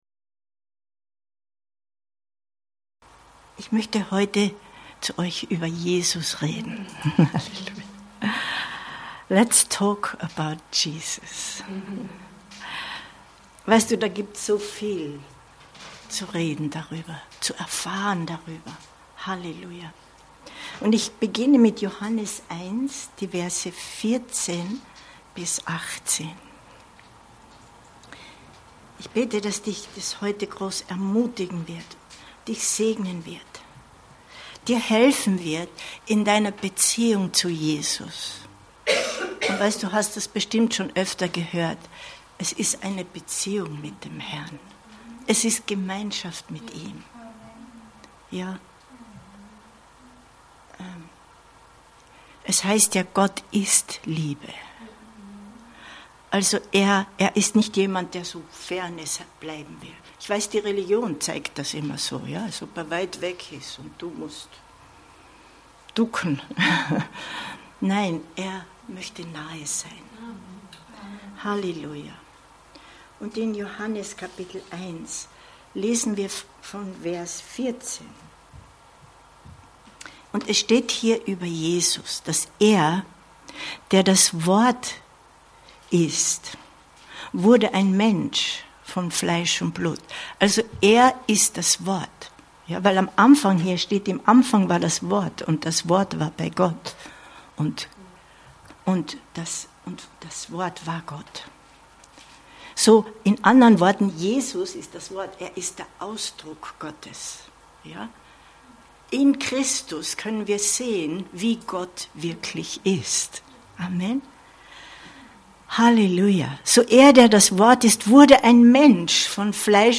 Gnade und Wahrheit durch Jesus Christus 16.07.2023 Predigt herunterladen